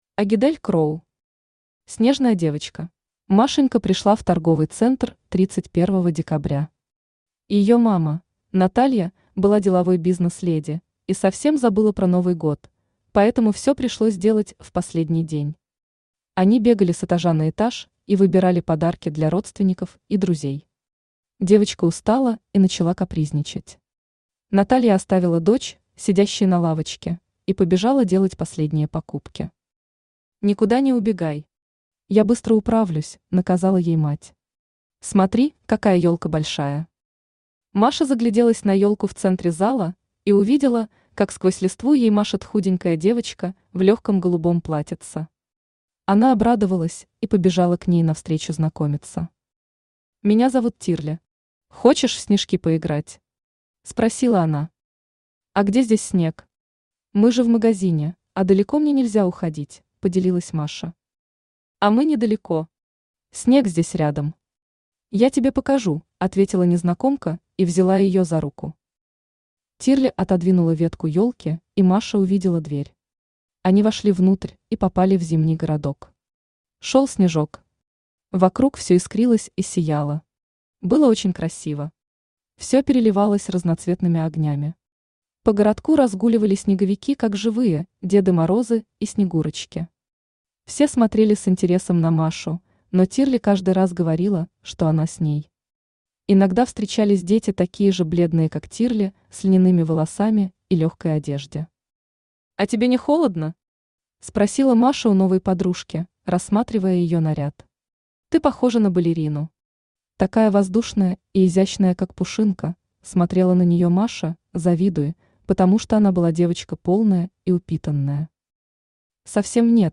Аудиокнига Снежная девочка | Библиотека аудиокниг
Aудиокнига Снежная девочка Автор Агидель Кроу Читает аудиокнигу Авточтец ЛитРес.